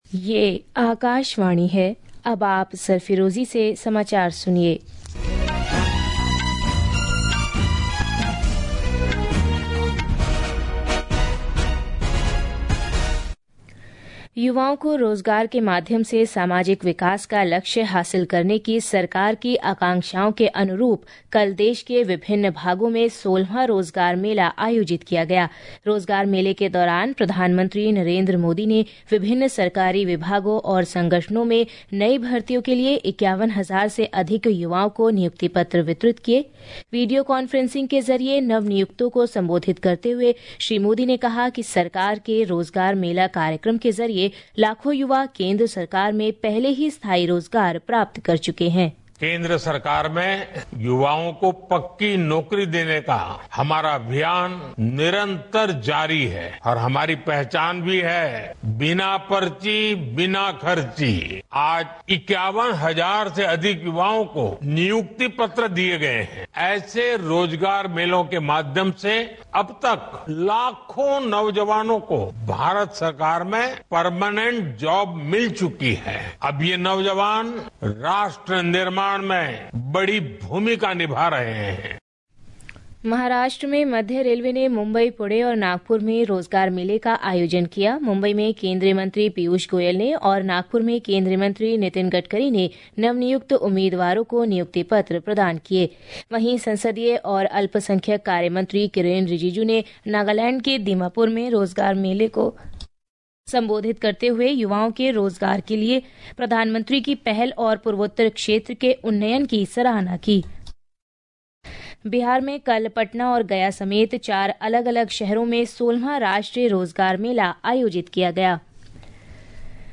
प्रति घंटा समाचार
प्रति घंटा समाचार | Hindi